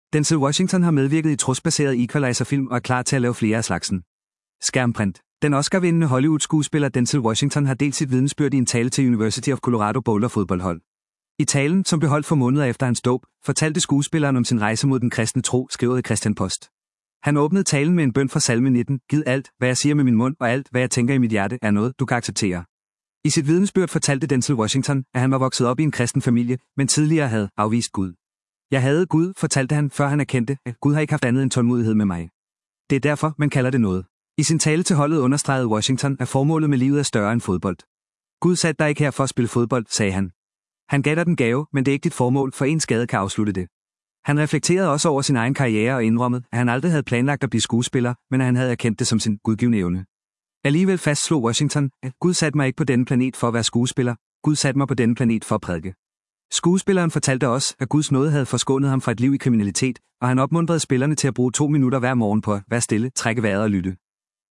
Den Oscar-vindende Hollywood-skuespiller Denzel Washington har delt sit vidnesbyrd i en tale til University of Colorado Boulder fodboldhold.